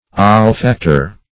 \Ol*fac"tor\